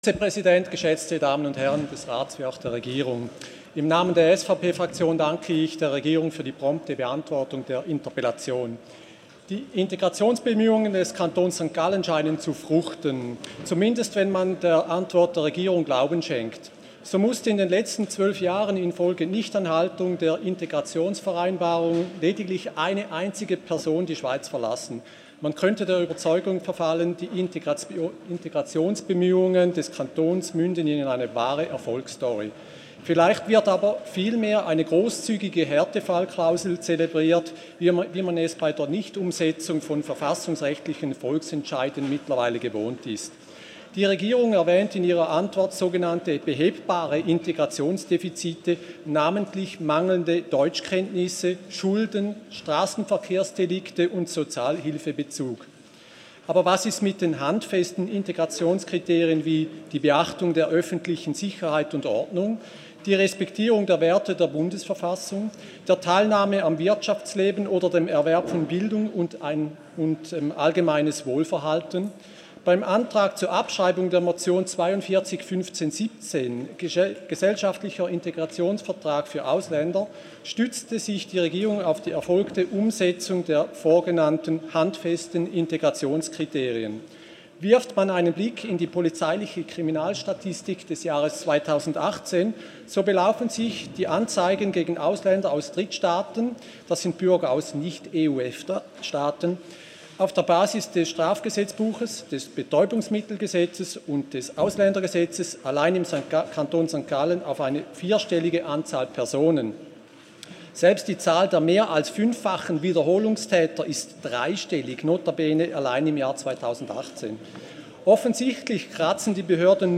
Session des Kantonsrates vom 16. bis 18. September 2019